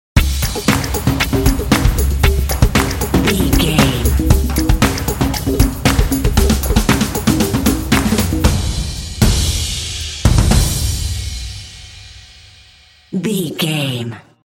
Atonal
driving
motivational
epic
drumline